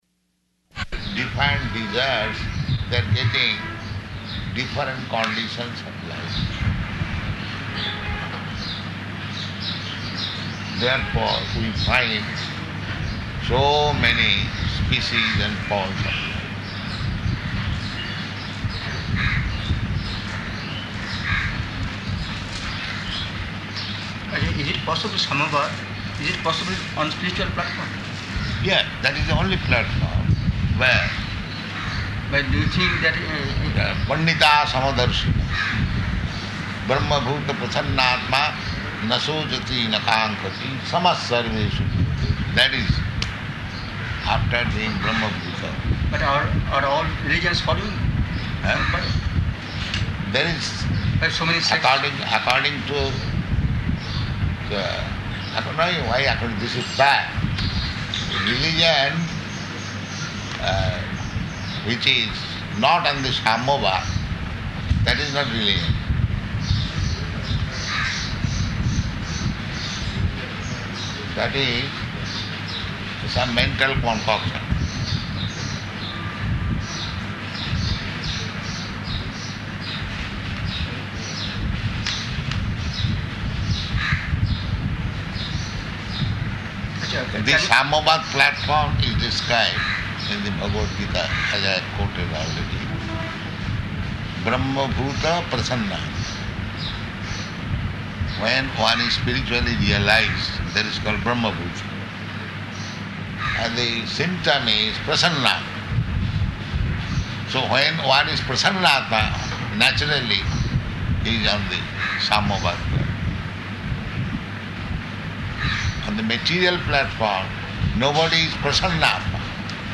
Room Conversation with Press Representative
Type: Conversation
Location: Calcutta